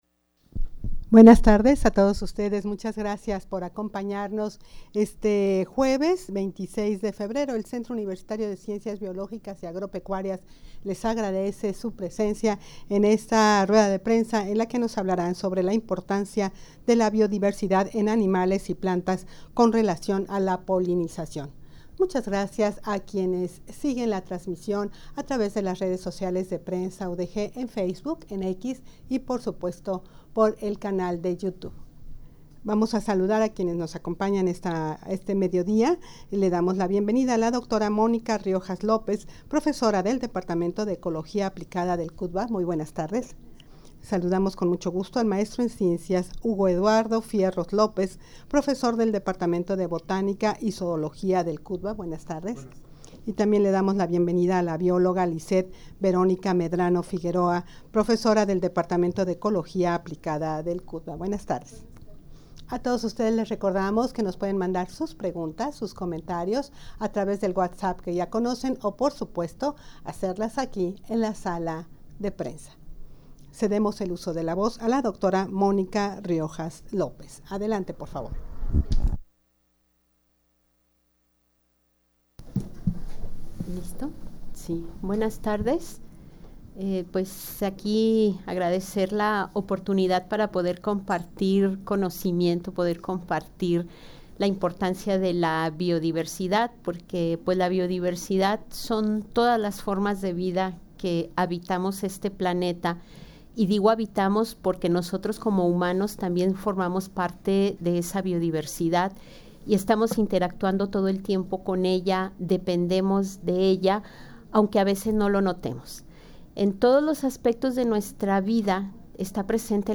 Audio de la Rueda de Prensa
rueda-de-prensa-importancia-de-la-biodiversidad-en-animales-y-plantas-con-relacion-a-la-polinizacion.mp3